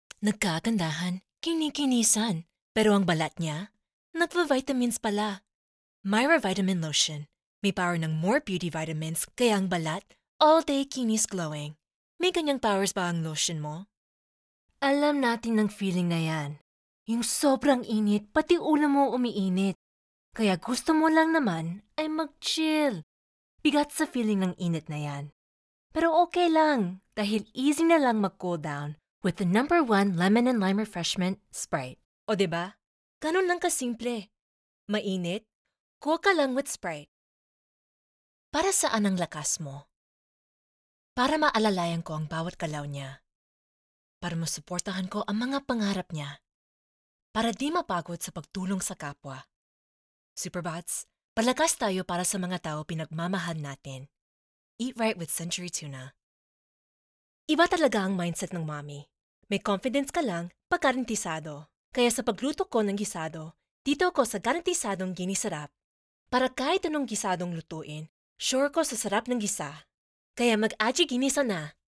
Female
Approachable, Assured, Bright, Conversational, Corporate, Deep, Energetic, Engaging, Friendly, Natural, Reassuring, Sarcastic, Smooth, Streetwise, Versatile, Warm, Young
Microphone: Audio Technica AT-2035
Audio equipment: Scarlett Solo Interface